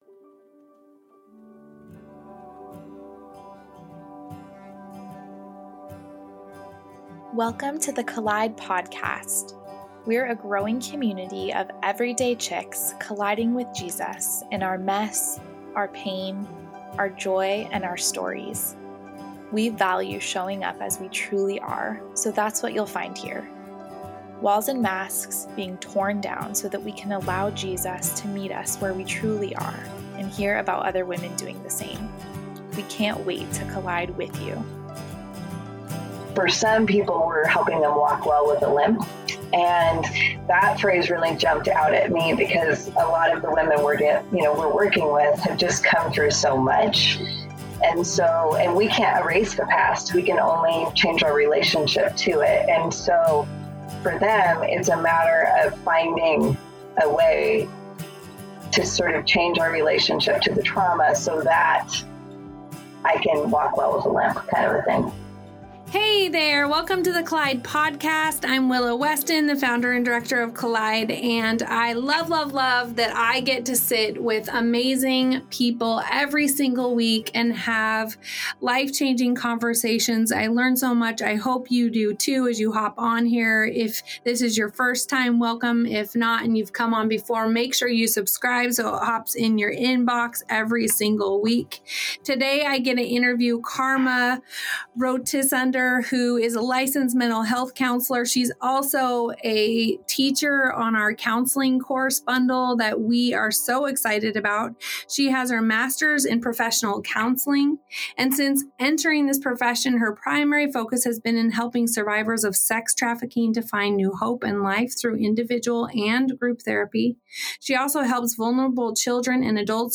and a wealth of experience to this conversation as she unpacks the complexities of trauma and the paths that lead to restoration.